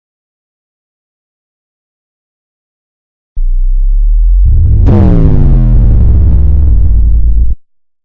Bass Drop Sound Effect Free Download
Bass Drop